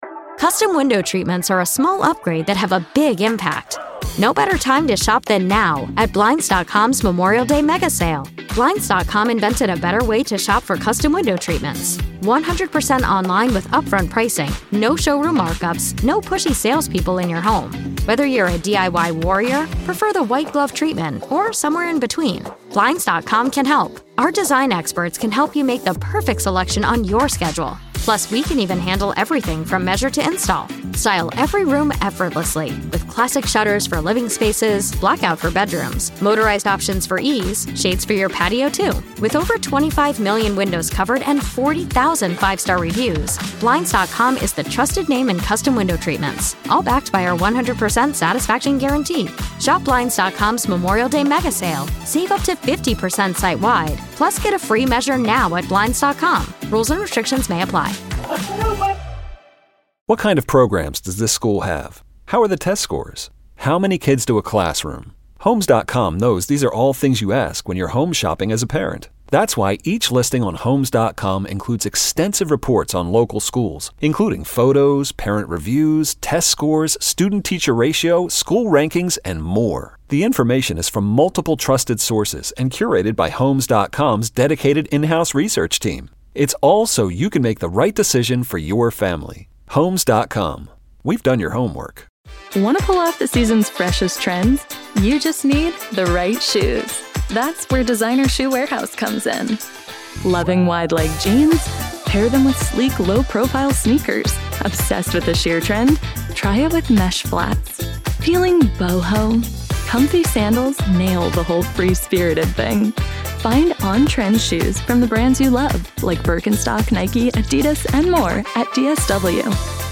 The Callers Chime In On Lions Ticket News